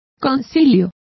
Complete with pronunciation of the translation of councils.